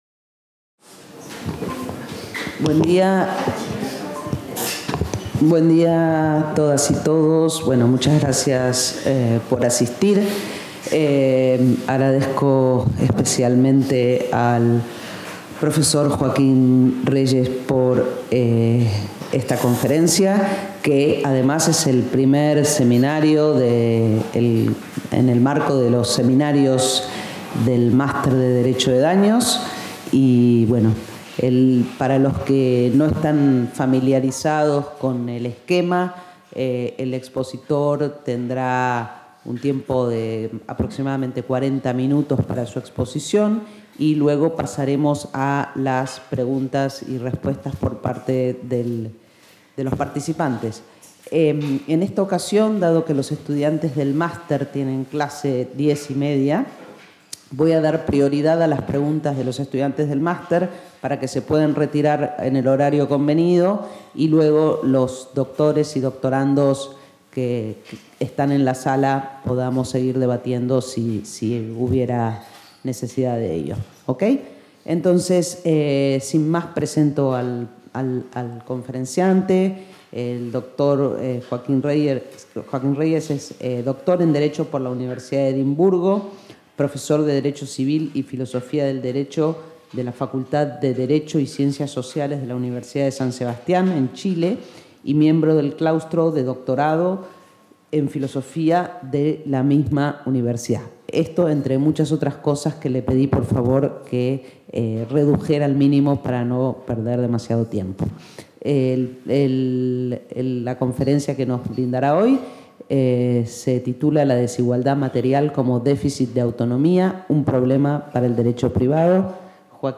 Within the seminars organized by the Master in Tort Law of the Faculty of Law of the UdG